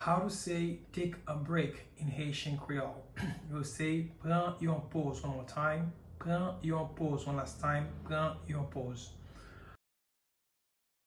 Pronunciation:
Take-a-break-in-Haitian-Creole-Pran-yon-poz-pronunciation.mp3